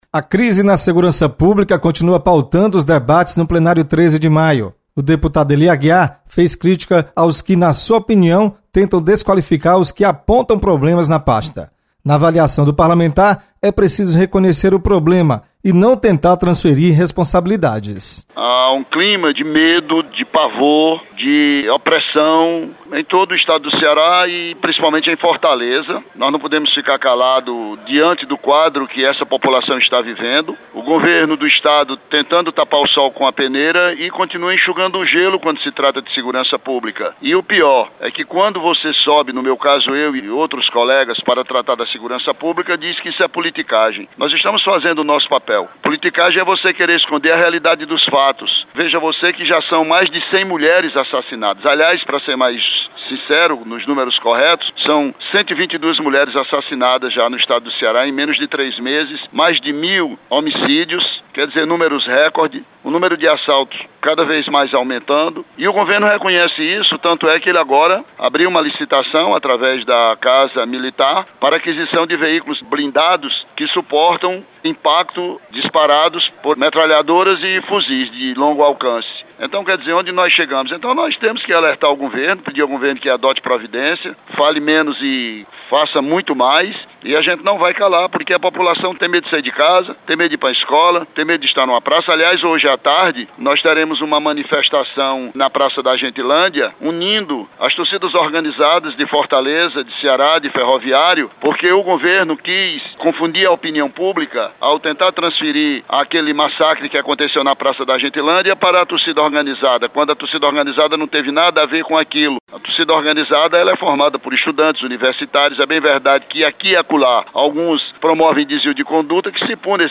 Deputado Ely Aguiar critica desqualificação da atuação parlamentar contrária à atual gestão da segurança pública. Repórter